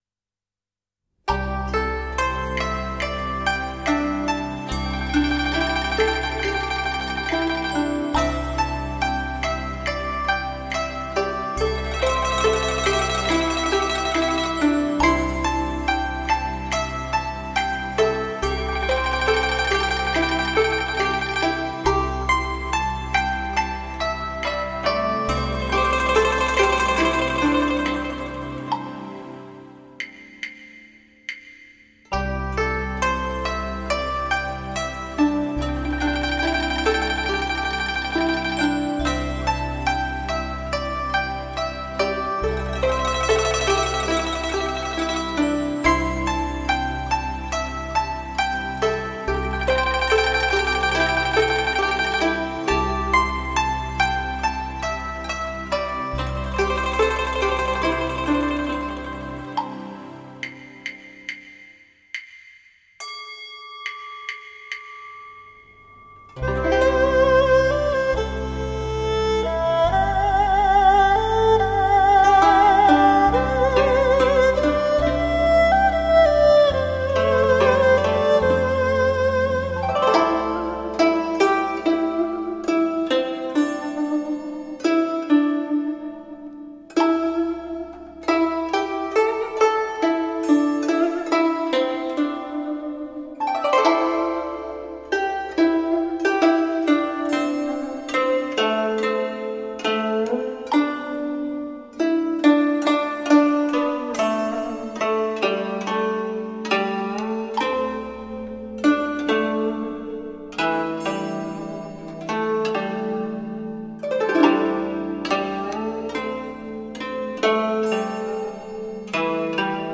天籁雅乐 柔美祥和
人声质感优雅大方，
每个乐章雍容有度，
含蓄细致，
宁静祥和的旋律中有无尽诗意，
录音十分细腻，
质感晶莹剔透，